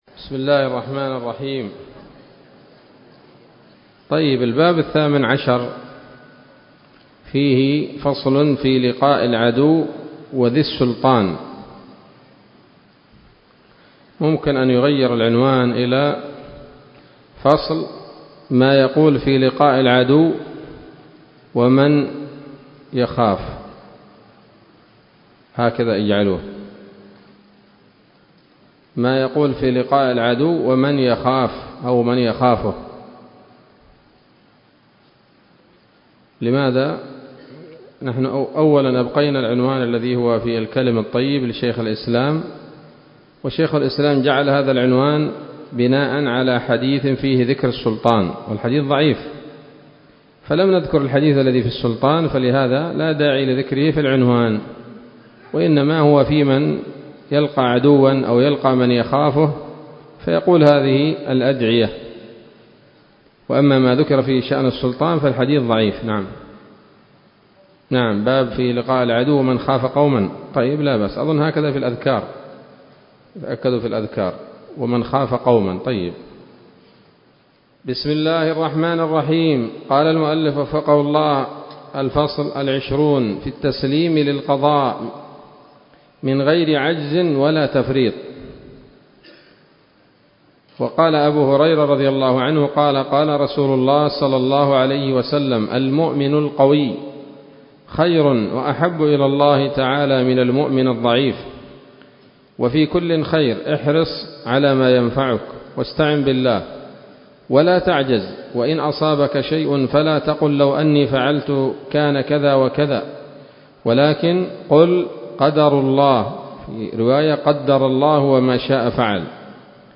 الدرس الثلاثون من رياض الأبرار من صحيح الأذكار